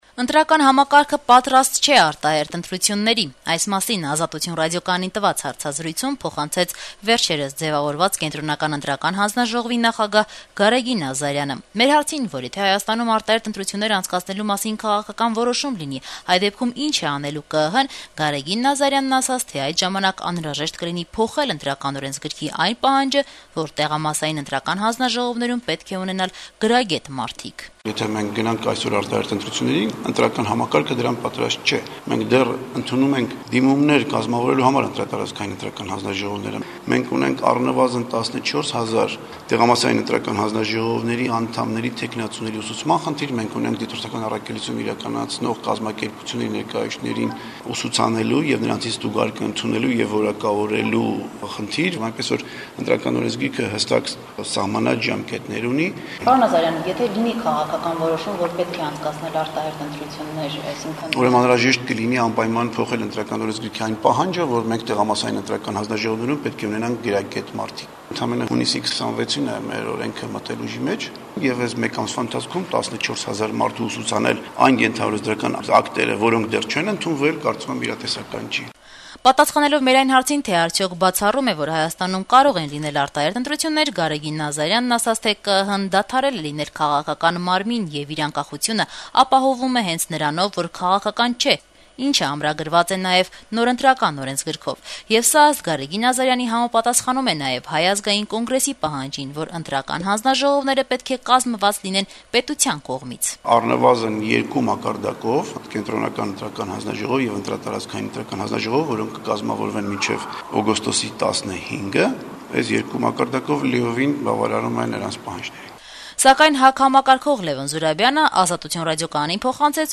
Գարեգին Ազարյանը «Ազատություն» ռադիոկայանի հետ հարցազրույցում պնդեց, թե ընտրական համակարգը պատրաստ չէ արտահերթ ընտրություններին: